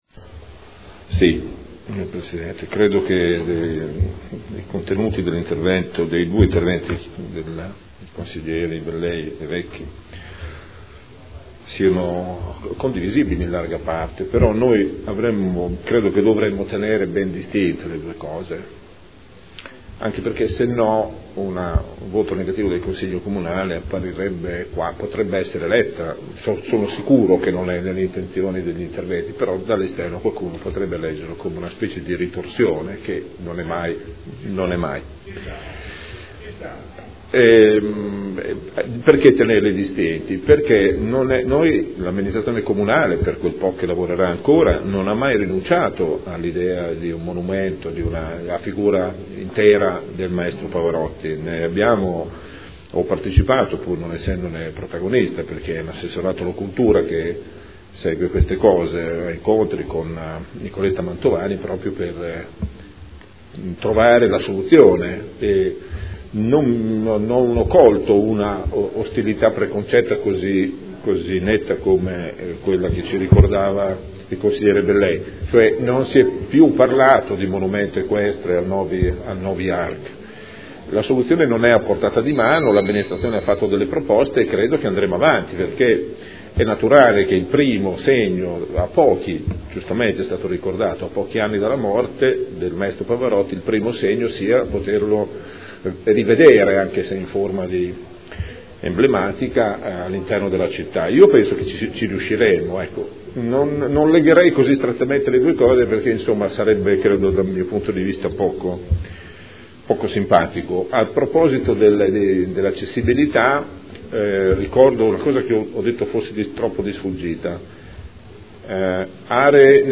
Gabriele Giacobazzi — Sito Audio Consiglio Comunale
Seduta del 31 marzo. Proposta di deliberazione: Proposta di progetto - Casa Museo del Maestro Luciano Pavarotti – Stradello Nava – Z.E. 2400 – Nulla osta in deroga agli strumenti urbanistici comunali – Art. 20 L.R. 15/2013. Replica